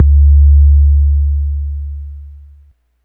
puredata/resources/808_drum_kit/classic 808/HBA1 08 bass02.wav at c0178eba08cc41a980875fbeacea19b9bc1cc284